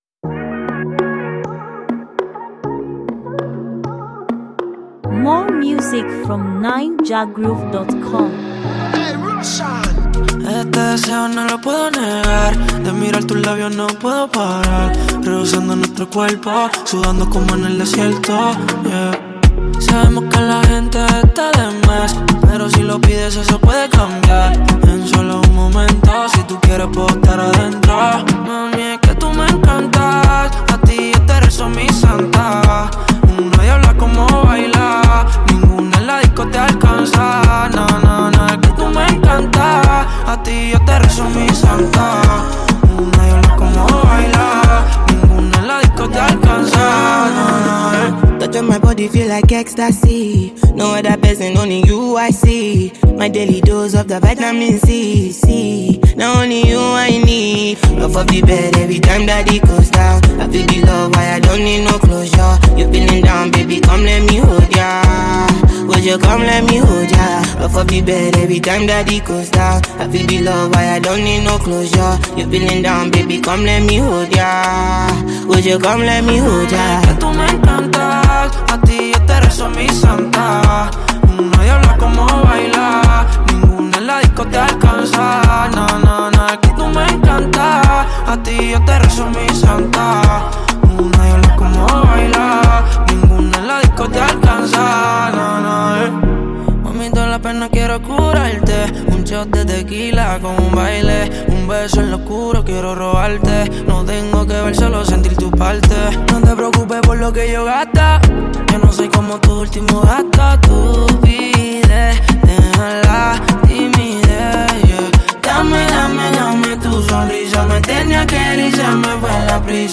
African-music